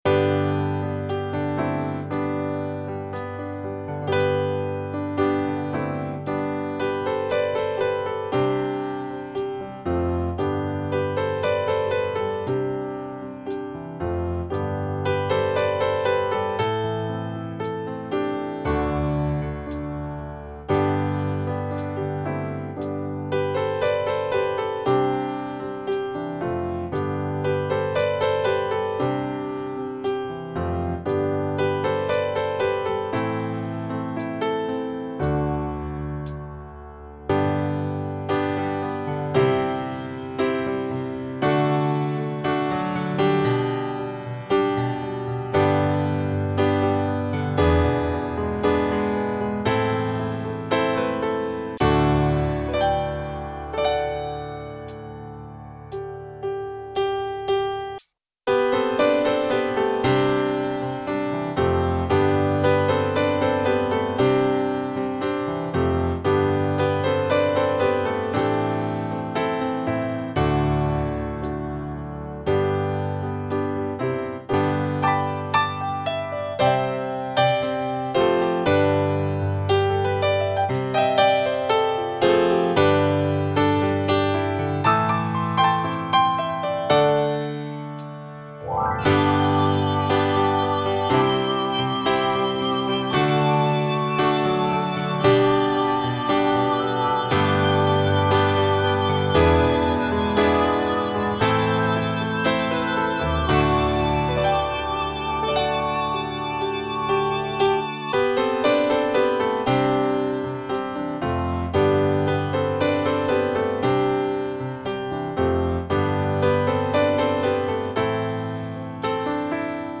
Übungsaufnahmen - Lass uns leben
Runterladen (Mit rechter Maustaste anklicken, Menübefehl auswählen)   Lass uns leben (Playback)
Lass_uns_leben__5_Playback.mp3